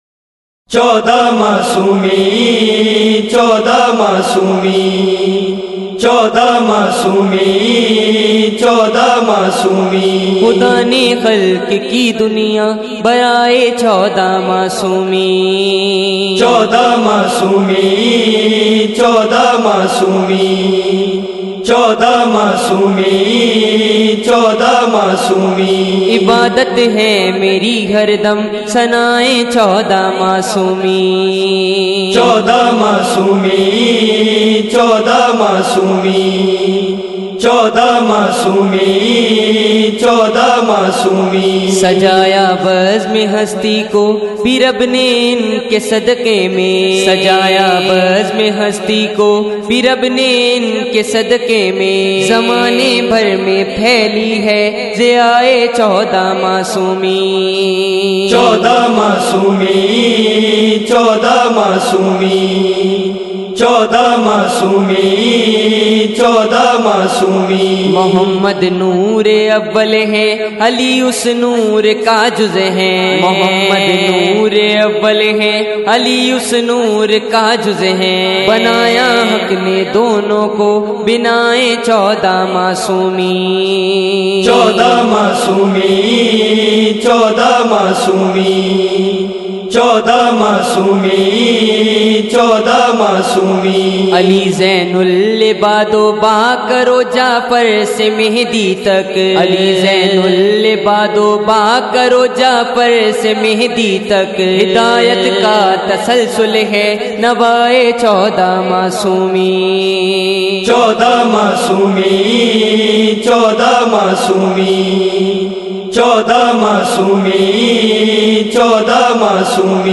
منقبتیں اور قصیدے